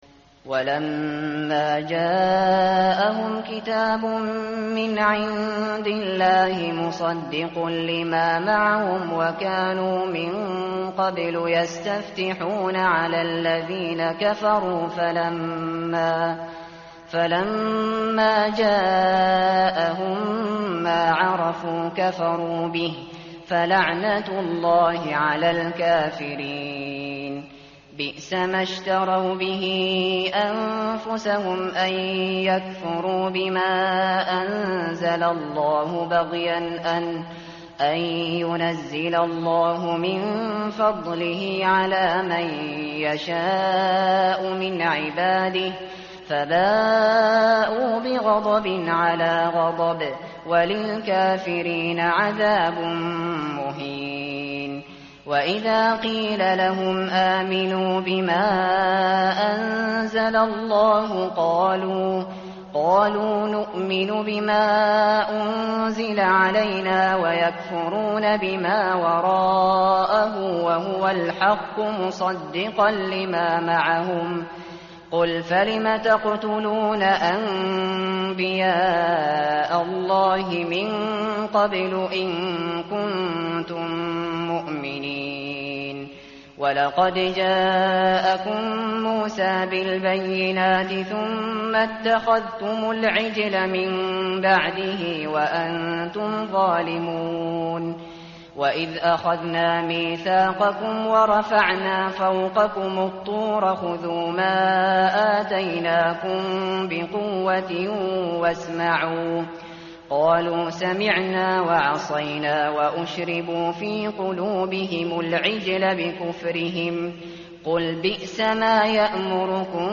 متن قرآن همراه باتلاوت قرآن و ترجمه
tartil_shateri_page_014.mp3